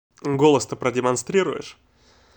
Спасибо за образец голоса.